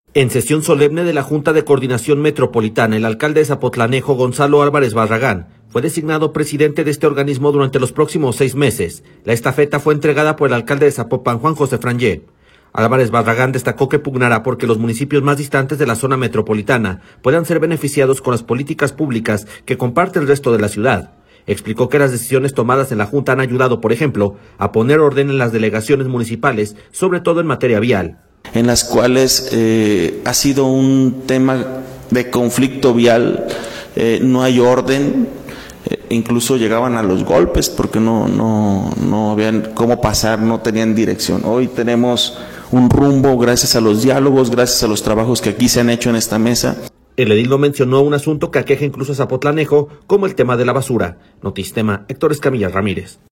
En sesión solemne de la Junta de Coordinación Metropolitana, el alcalde de Zapotlanejo, Gonzalo Álvarez Barragán, fue designado presidente de este organismo durante los próximos seis meses.